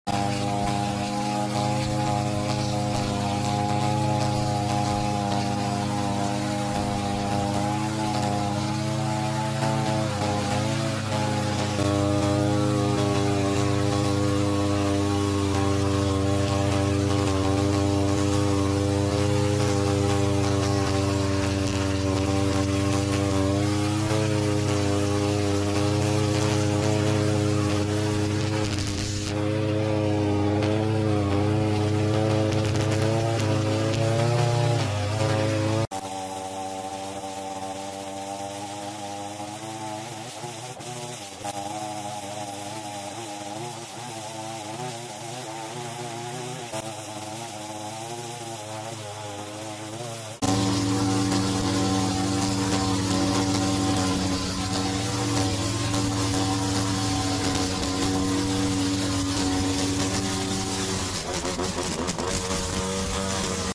Grass cutting asmr video sound effects free download